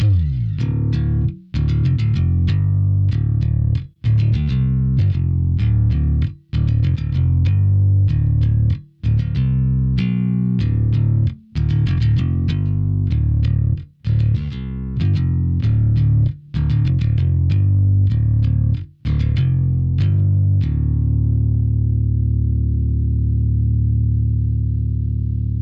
Modern Rock (Glam?)